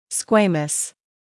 [‘skweɪməs][‘скуэймэс]чешуйчатый, сквамозный; чешуйчатая кость